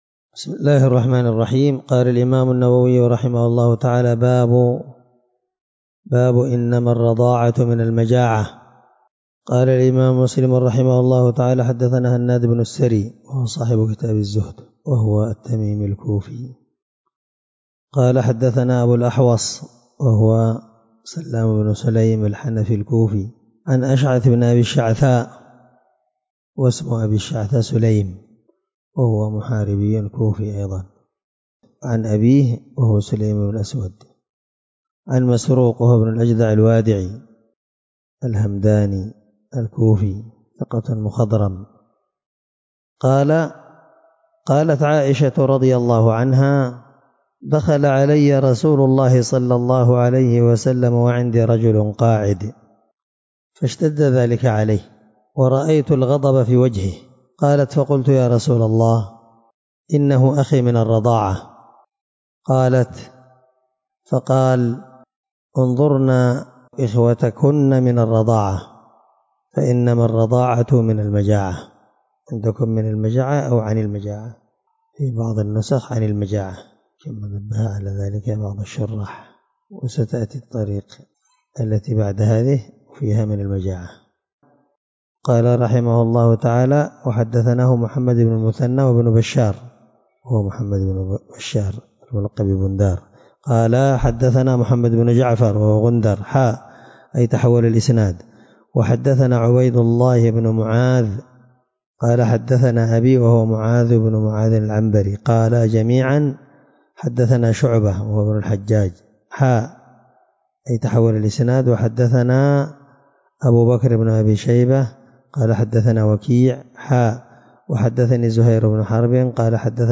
الدرس8من شرح كتاب الرضاع حديث رقم(1455) من صحيح مسلم